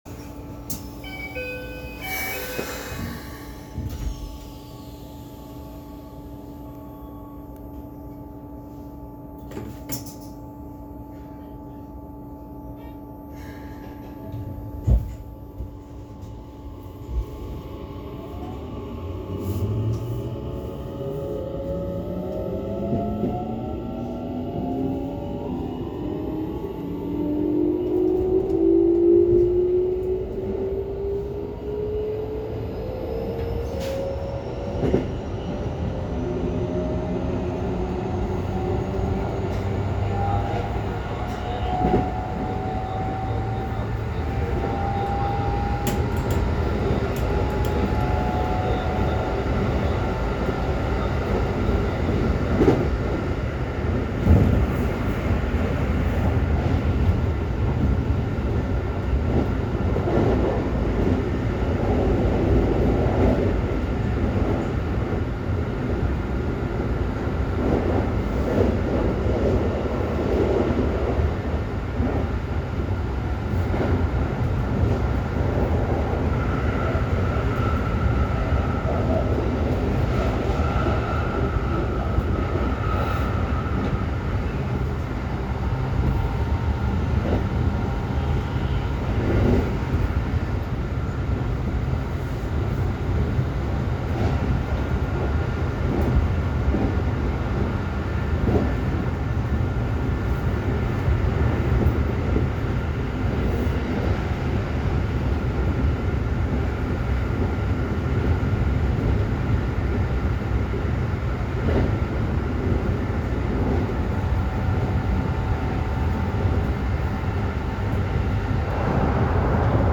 ・1000形（東芝IGBT）走行音
【本線】京急鶴見→京急川崎
1000形の機器更新車のうち、4両編成の編成には東芝IGBTになっている編成が存在しています。1367編成の東芝PMSMとは異なるごく普通の物ですが、むしろこちらの音の方が珍しいのかもしれません。